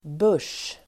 Uttal: [bör_s:]